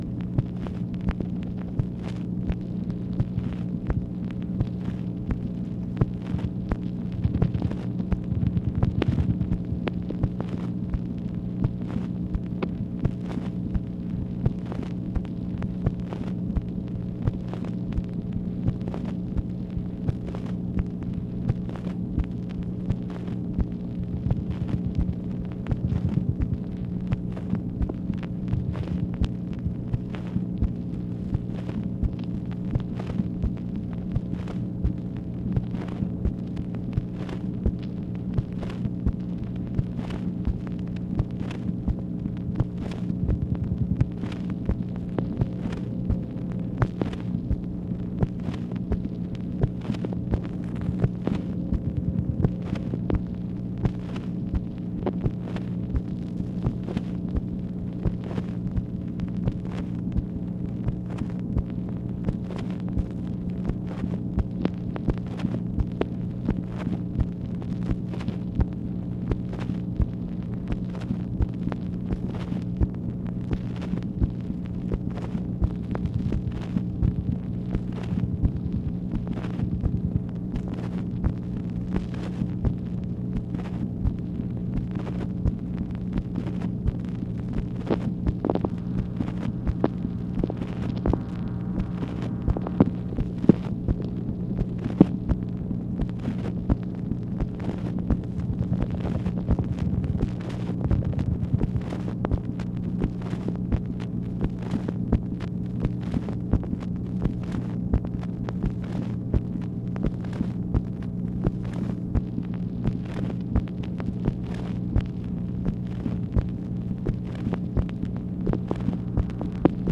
MACHINE NOISE, August 4, 1965
Secret White House Tapes | Lyndon B. Johnson Presidency